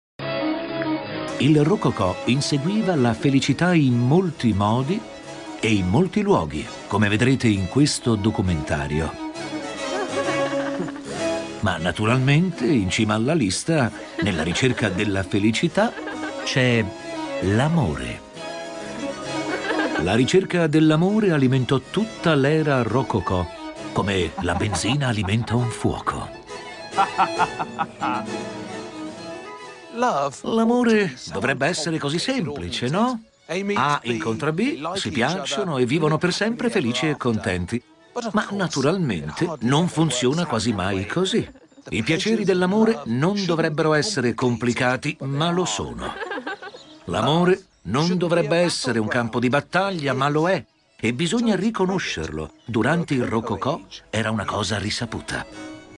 • Voce narrante in "Luci e ombre del Rinascimento", "Rococň - Viaggi, Piaceri e Follie"